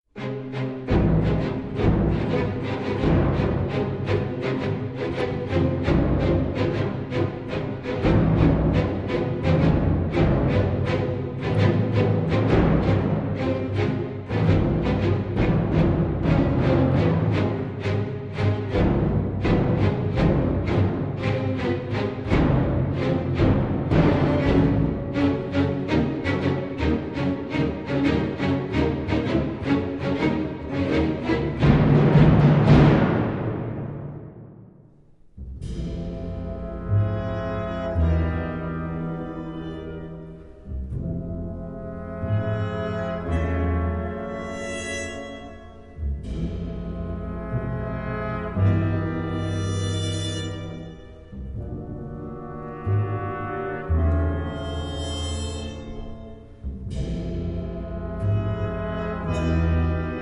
jazz compositions